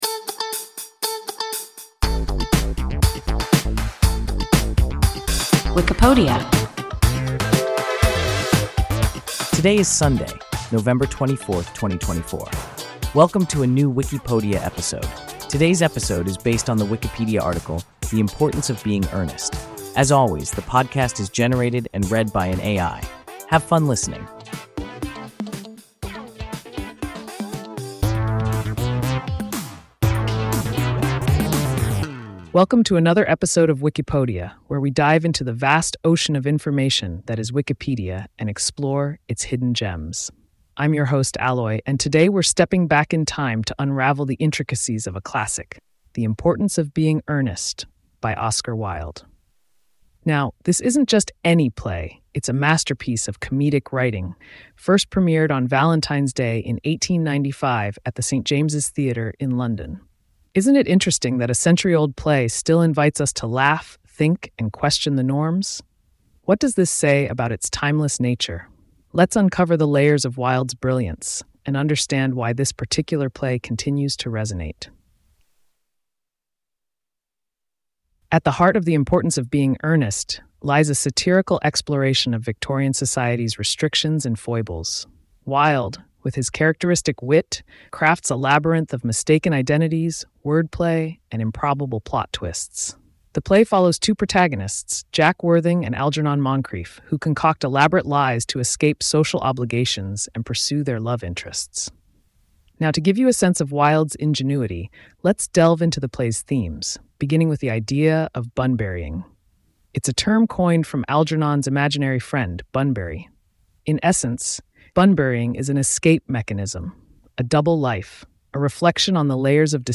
The Importance of Being Earnest – WIKIPODIA – ein KI Podcast